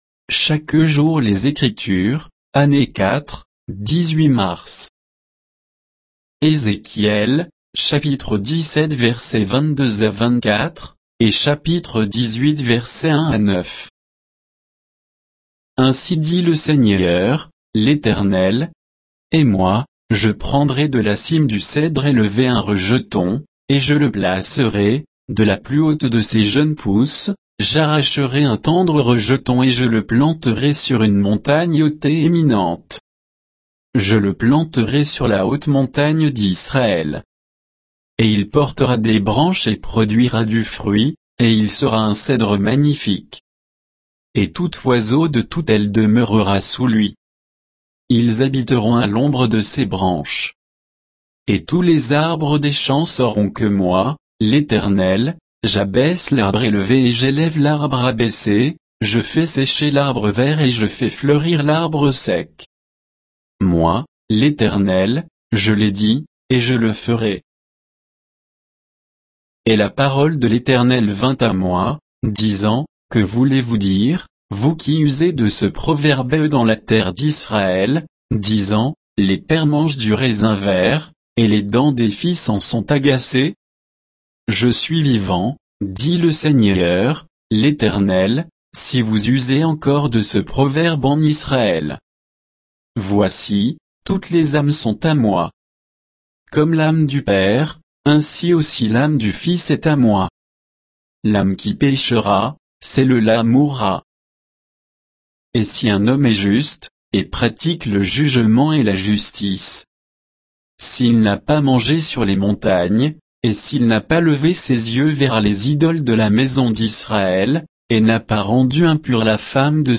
Méditation quoditienne de Chaque jour les Écritures sur Ézéchiel 17, 22 à 18, 9